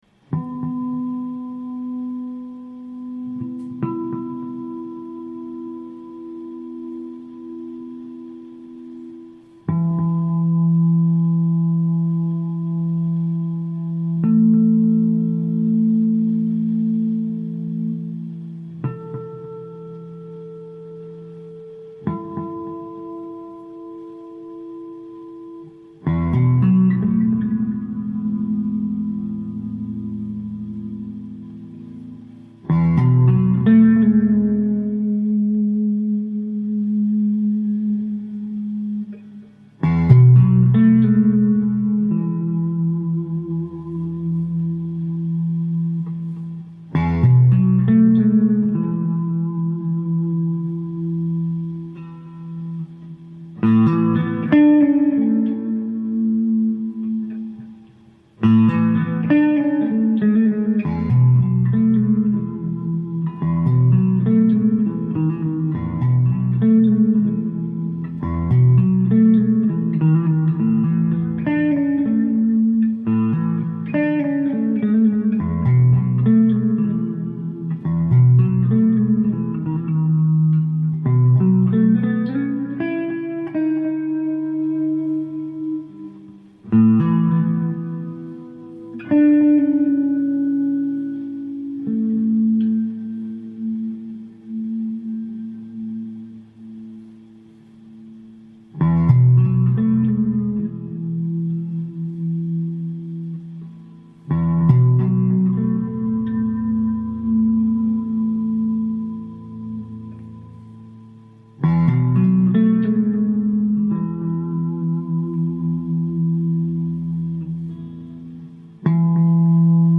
an expression in sound of the sadness in my heart on this Bastille day <3